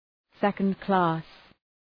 Προφορά
{‘sekəndklæs}